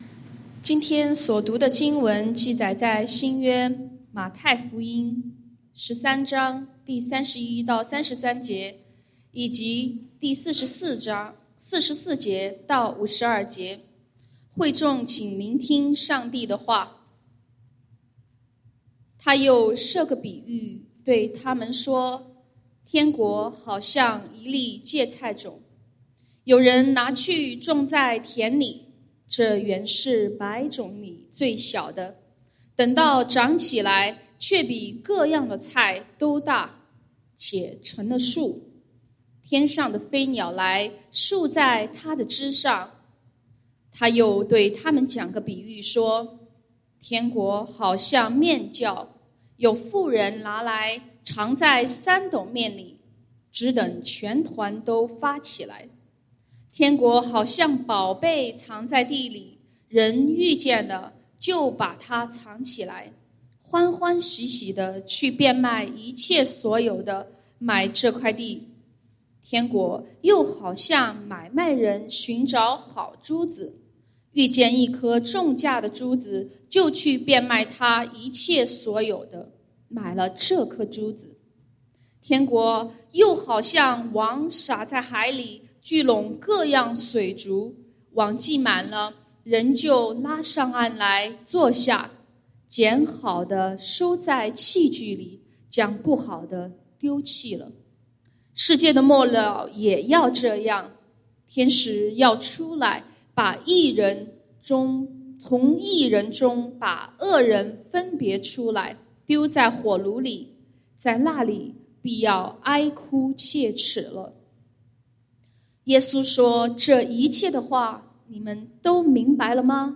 講道經文：《馬太福音》Matthew 13:31-33, 44-52